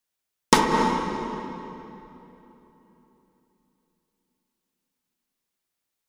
今回はプリセットのLarge Hall Brightというものをベースに画像のような設定にしております。
スネア音（リバーブ有）